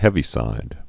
(hĕvē-sīd)